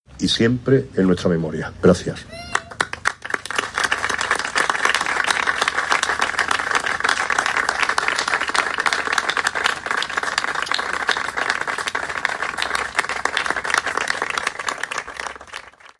Previamente, abriendo el acto, el alcalde Manolo Barón intervino explicando la importancia del hecho de volver a convocar y realizar un acto que pretende recordar a todas las víctimas del terrorismo, reincidiendo en que sigue estando presente en la sociedad de nuestros días en forma de otras amenazas globales.
Cortes de voz